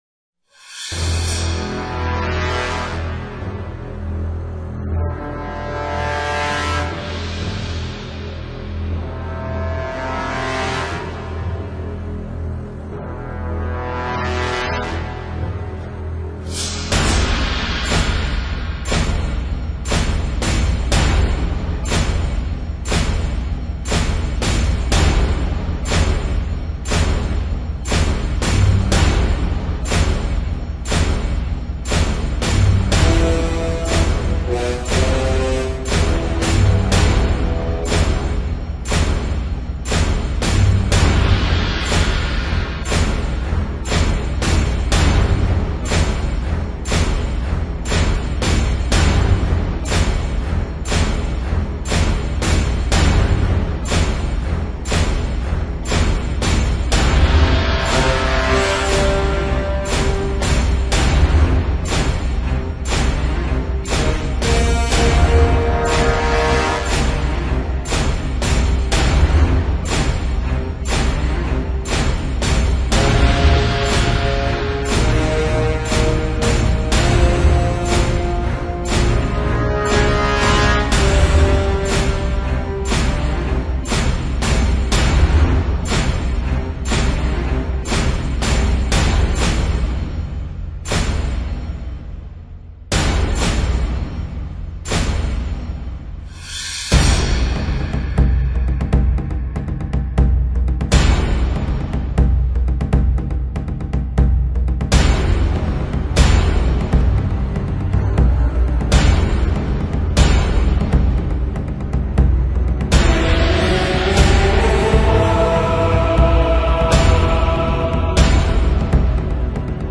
موسیقی بیکلام
موسیقی حماسی